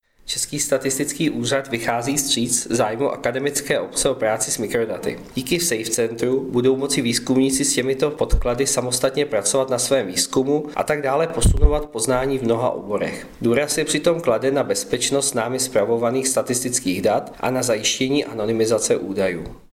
Vyjádření Marka Rojíčka, předsedy ČSÚ, soubor ve formátu MP3, 644.94 kB